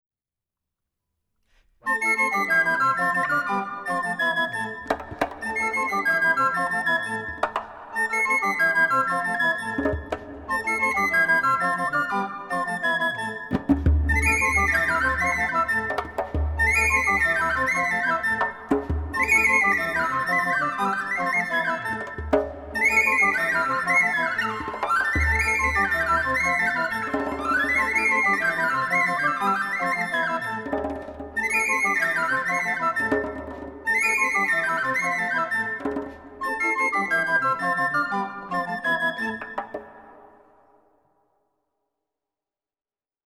recorders
zarb, daf & djembé
palmas & finger cymbals
Villancicos & Romances
microphones: Neumann, AKG, Schoeps